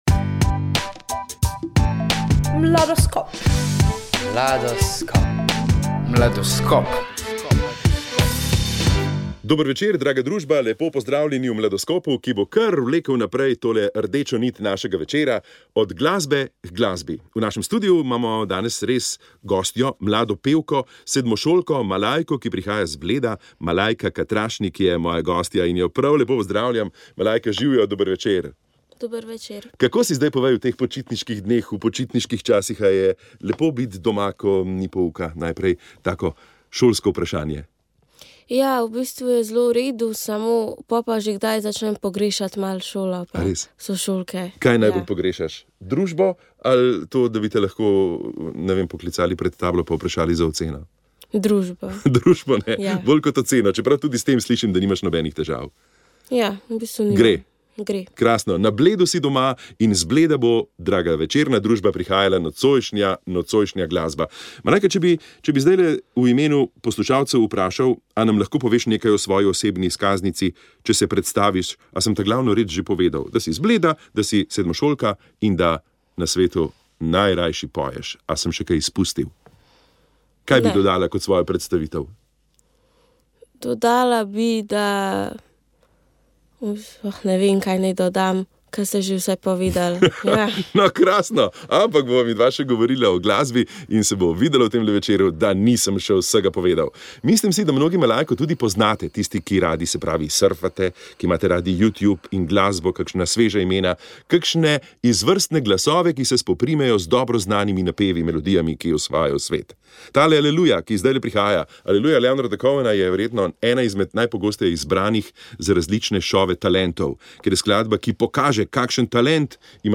pričevanje